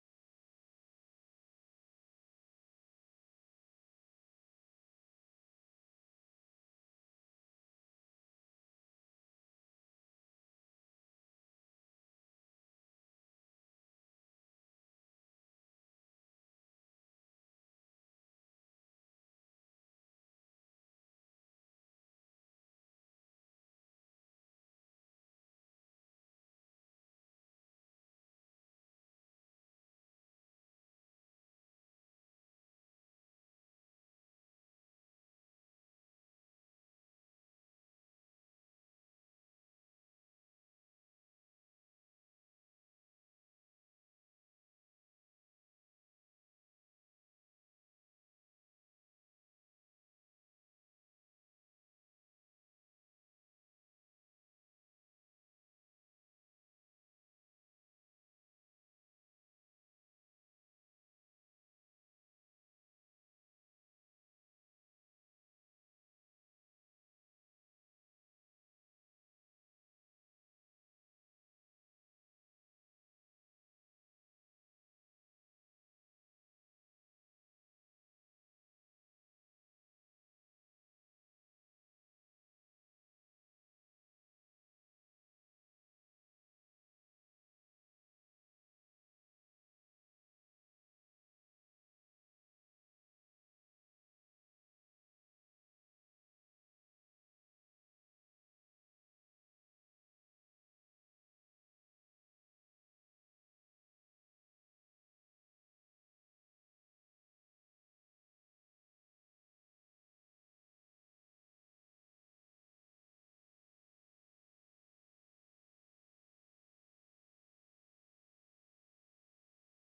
Vánoční zvonek pro kouzelný Štědrý večer: MP3 ke stažení
Nastavili jsme ho tak, že obsahuje 5 minut ticha a až poté začne jemně cinkat.
Zvuk je čistý, jasný a díky prodlevě máte jistotu, že budete v okamžiku „zázraku“ sedět všichni pohromadě.
Vánoční zvonek MP3 ke stažení s prodlevou (5 minut ticha, pak zvonění) je ideální volbou pro Štědrý večer.
5_minut_ticha_pak_zvonek.mp3